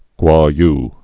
(gwôy) also Kuo·yu (kwô-)